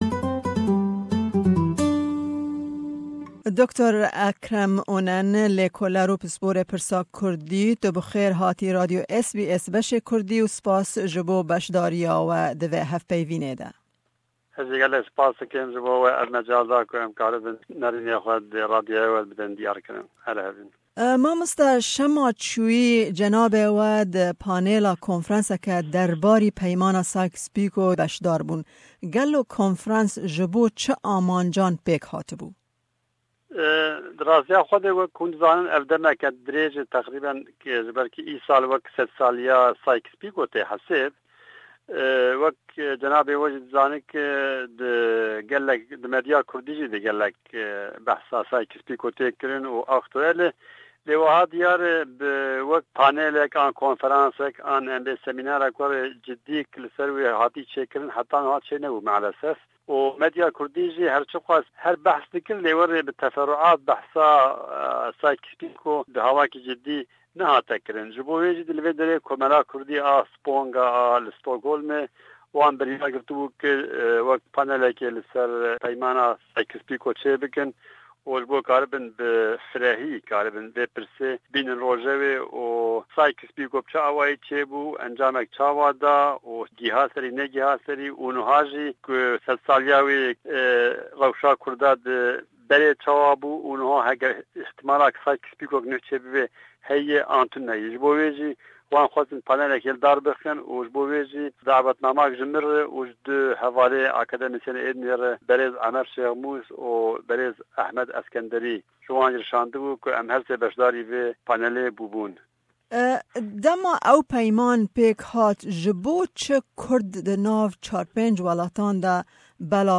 me hepeyvînek derbarê konfirensa sebaret bi peymana Sykes-Picot ku li Swêdê pêk hatî û pêwindiya peymanê bi kurdan de çiye pêk anî.